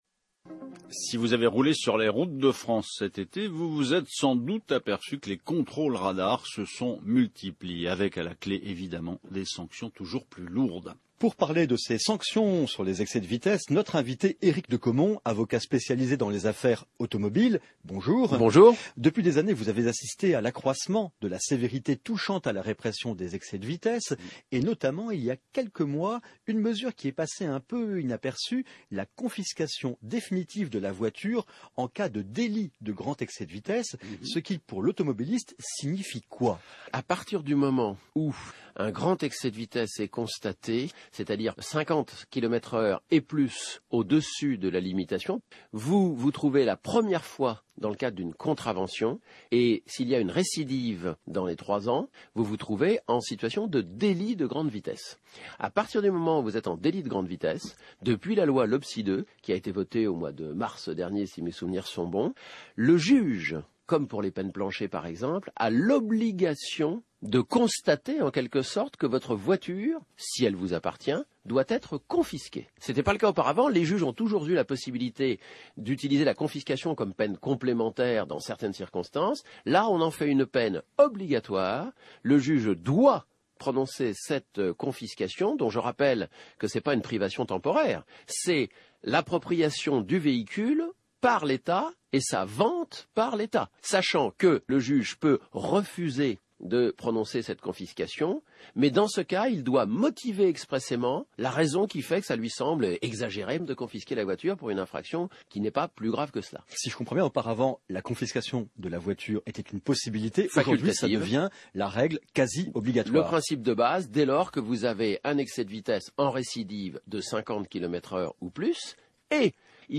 est intervenu sur France Info dans la chronique